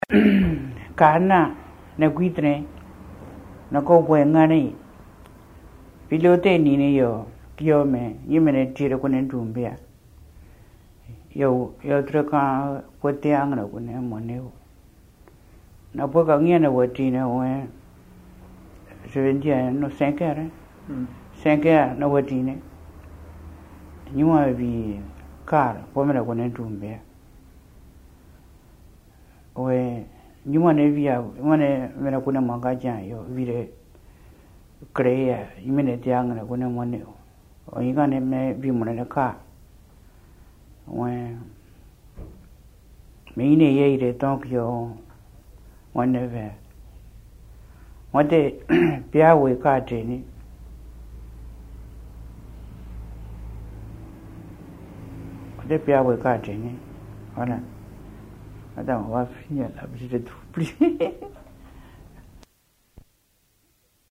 Accueil > Dialogue > Dialogue > Drubéa